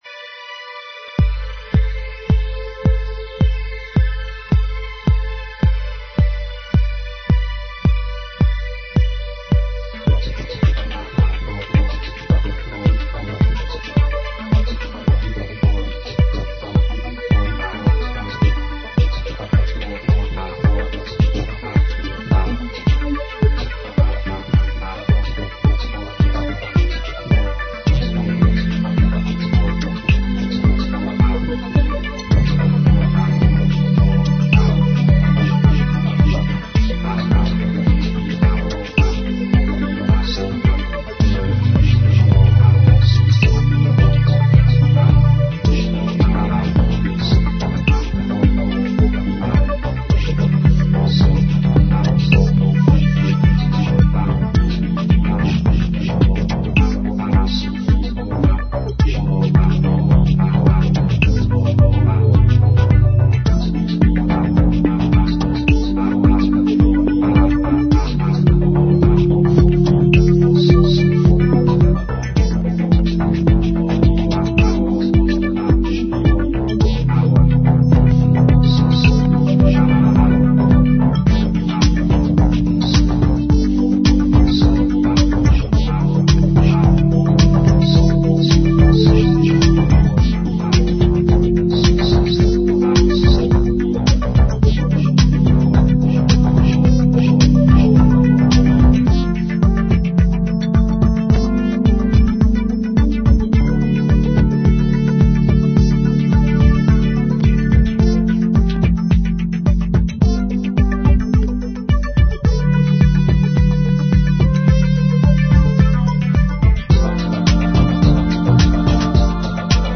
dance/electronic
Originally part two of another track, hence the pad lead in.
Ambient
IDM